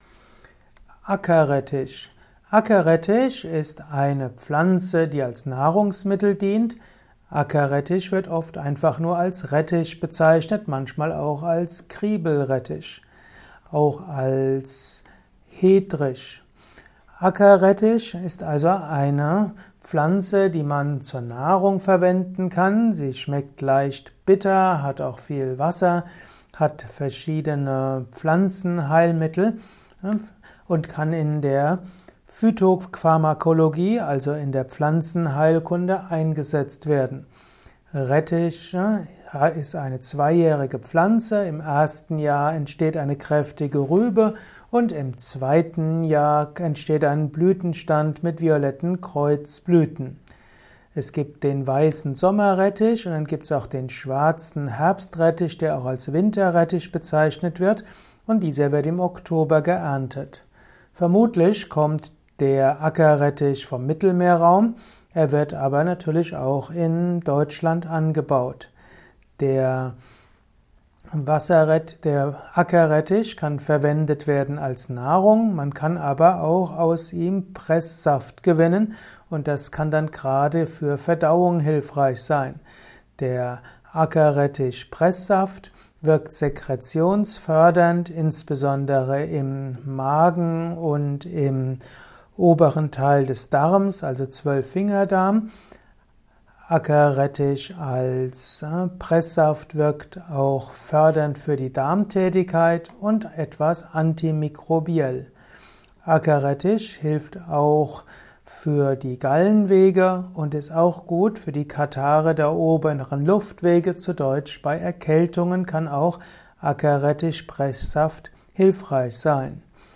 Ackerrettich - Erfahre mehr zum Thema Ackerrettich in diesem Kurzvortrag.